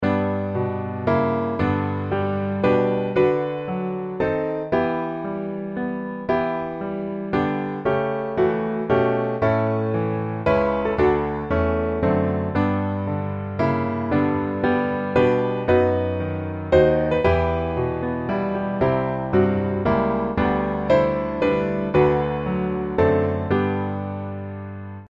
Piano Hymns
G Major